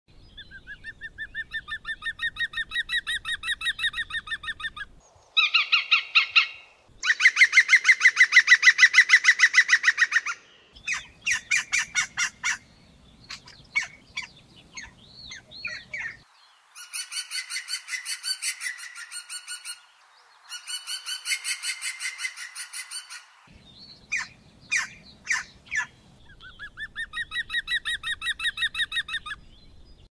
Nelle precedenti informative abbiamo già visto il picchio Verde (Picus viridis), ma sapete che potete facilmente imparare a riconoscere la sua presenza grazie al suo caratteristico canto? Assomiglia a una grassa risata, per questo è chiamato il "pagliaccio del bosco"!
QR4 - Canto del Picchio Verde - Richiamo.mp3